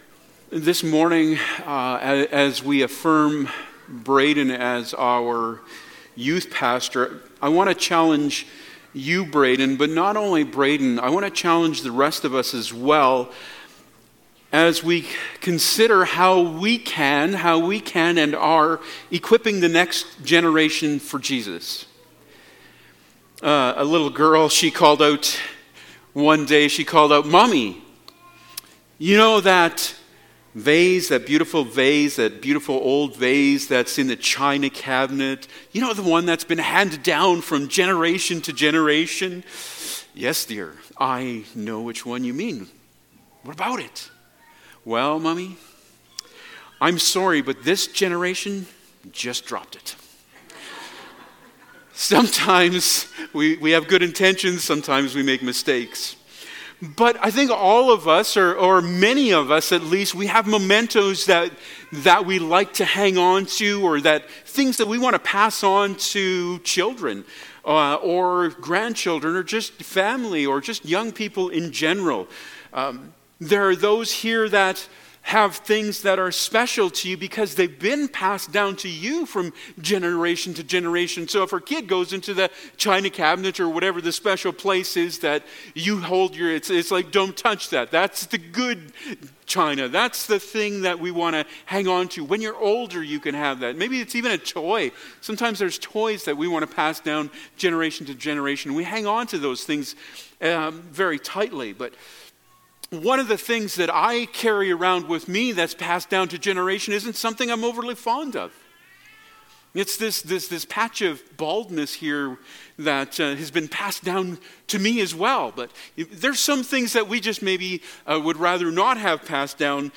1 Peter 5:1-4 Service Type: Sunday Morning « I Am the Way